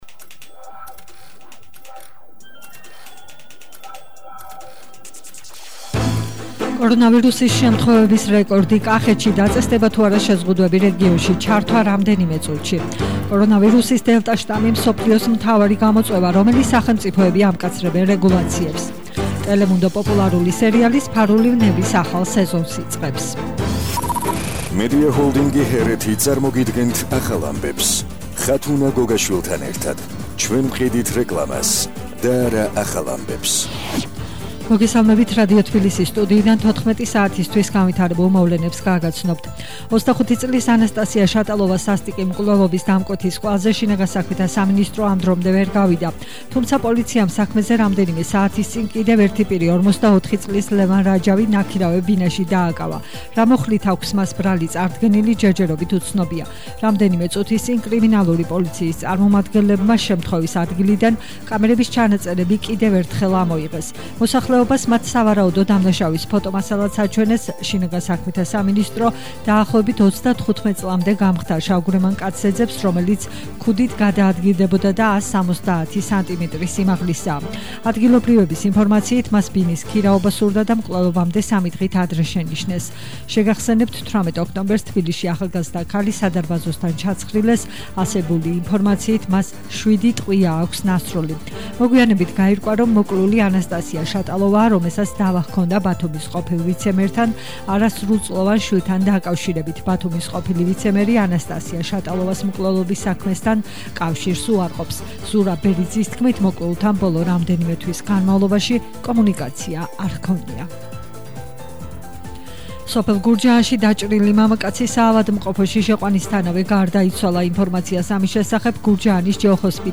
ახალი ამბები 14:00 საათზე –19/10/21 - HeretiFM